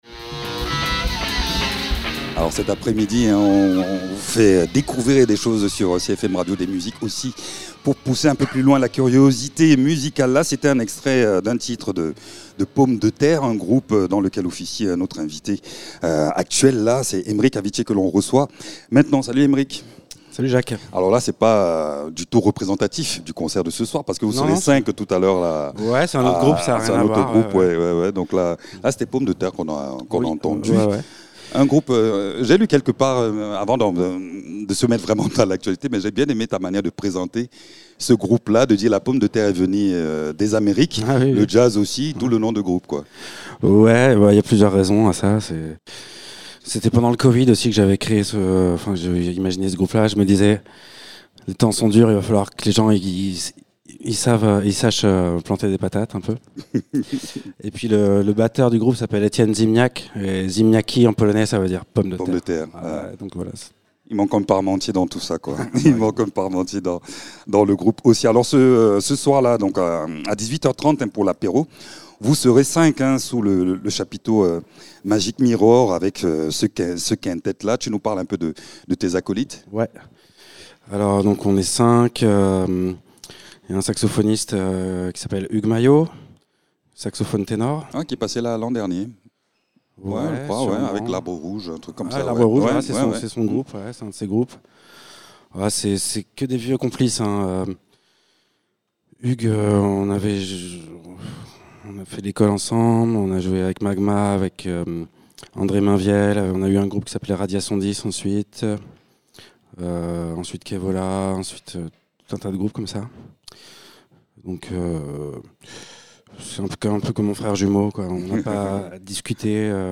trompettiste.